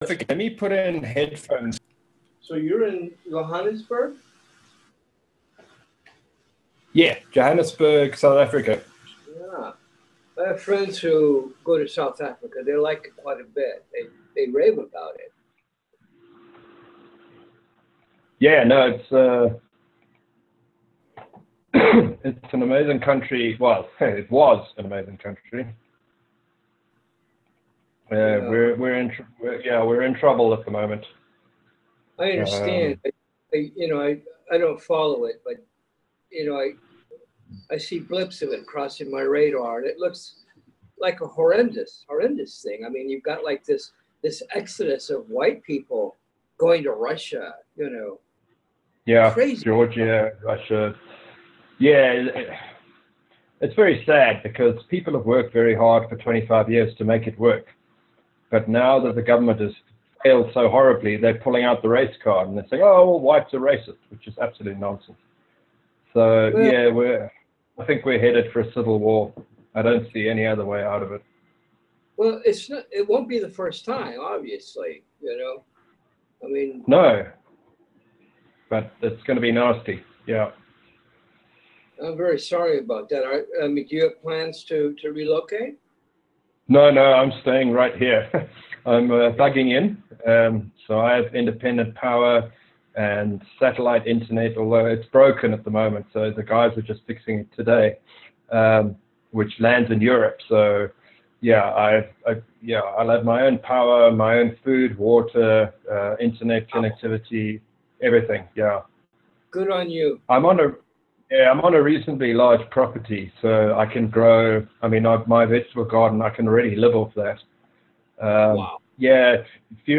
An interview with the original Q evangelist